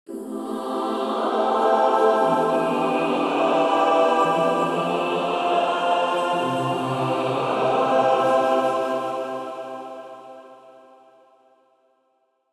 Reverieの最大の特徴は、「ブダペスト・スコアリング・クワイア」と共に録音されていることです。
美しいクワイアサウンドから実験的な音響表現まで、その多彩な表現力を実際にいくつかのプリセットで聴いてみてください。
このように、神秘的な合唱の美しさに加えて、CUBEならではの実験的な加工が施されたプリセットも収録されています。